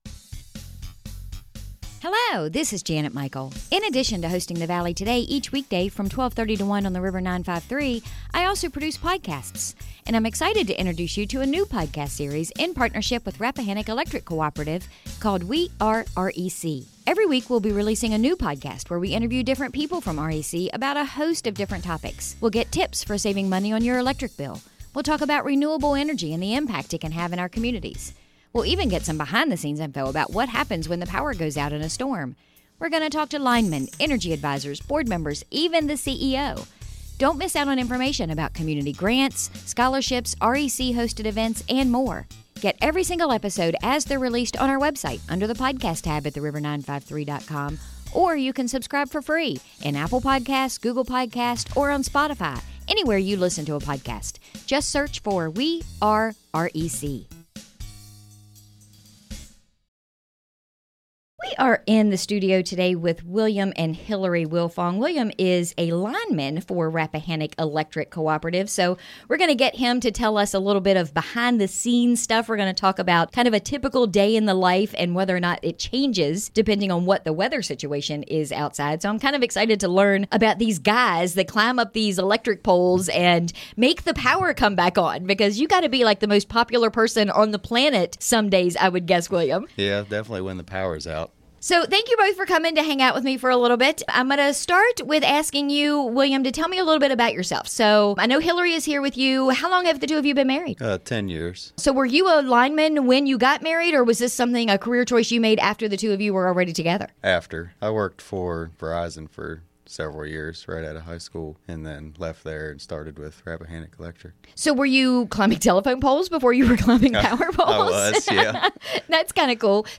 We were in the studio